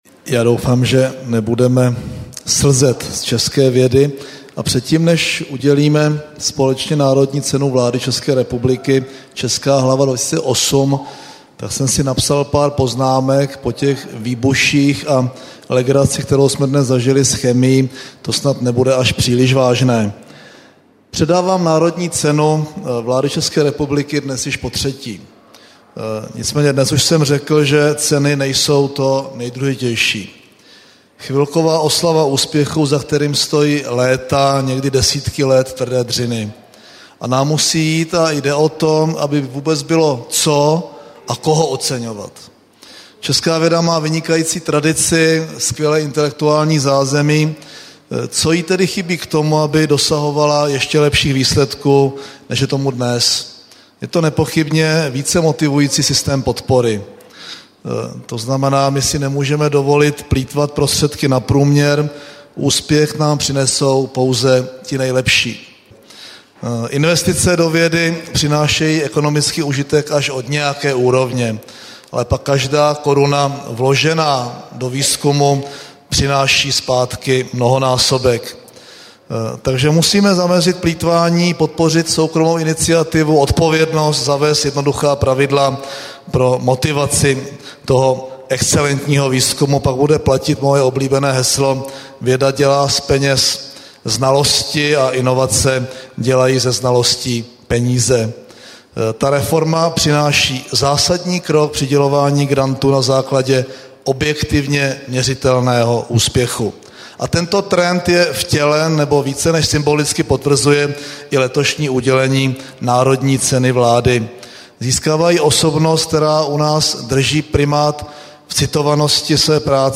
Projev premiéra Mirka Topolánka
Slavnostní projev premiéra